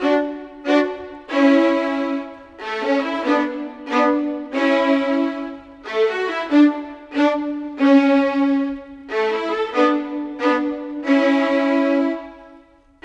Rock-Pop 06 Violins 02.wav